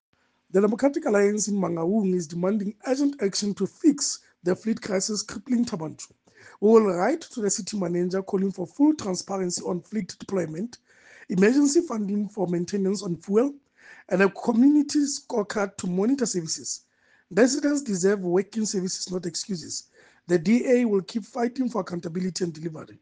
Sesotho soundbites by Cllr Kabelo Moreeng.